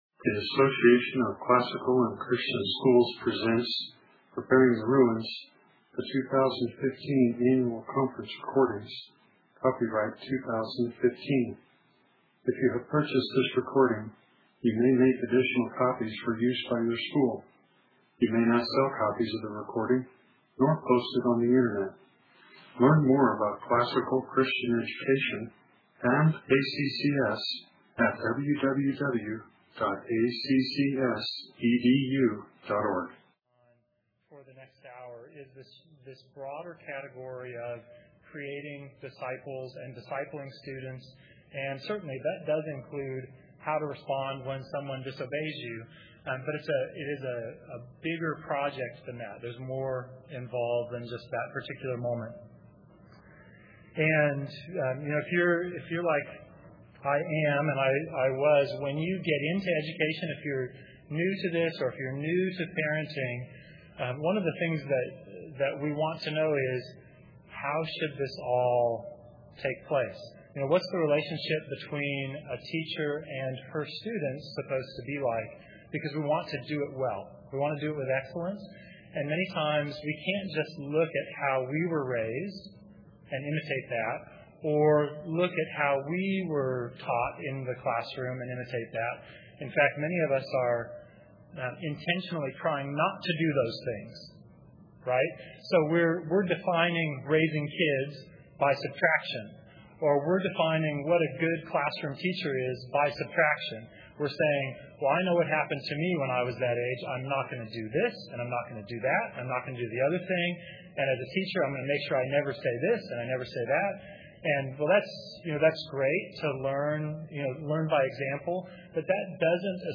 2016 Practicum Talk, 1:05:13, All Grade Levels, Virtue, Character, Discipline